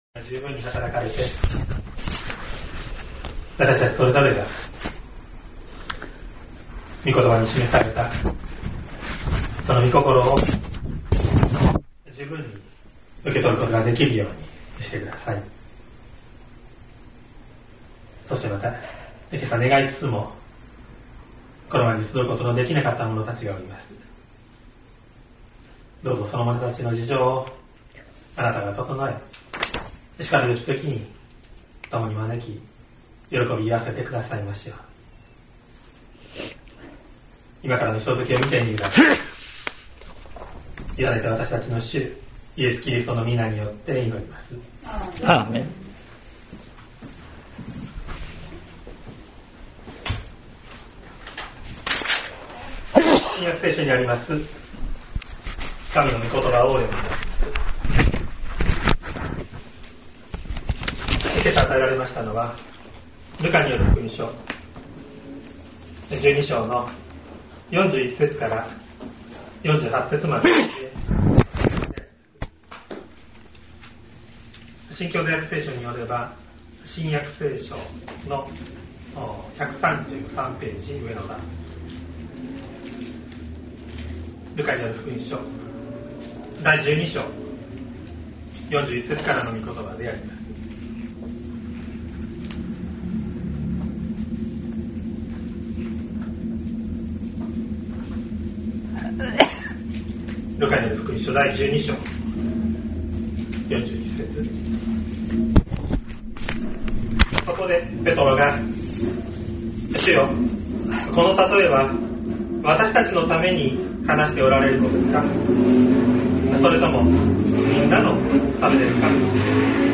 2024年10月20日朝の礼拝「主イエスの求めておられること」西谷教会
音声ファイル 礼拝説教を録音した音声ファイルを公開しています。